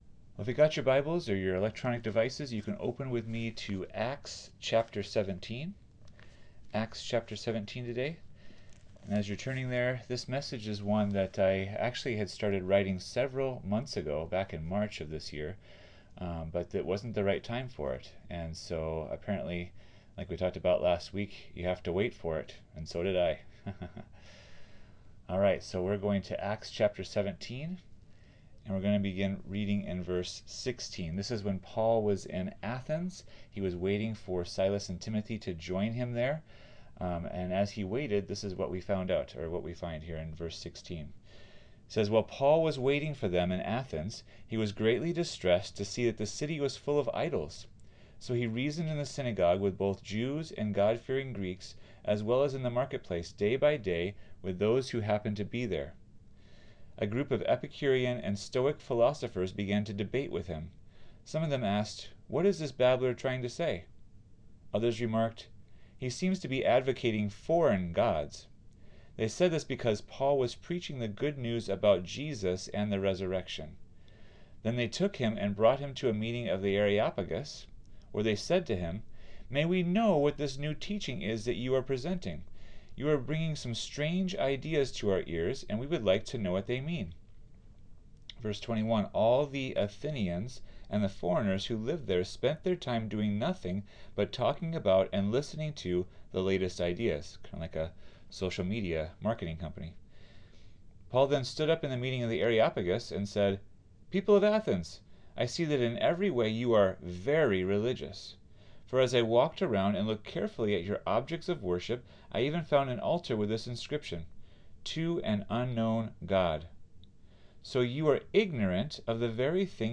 2024 Seeking Preacher